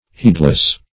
Heedless \Heed"less\, a.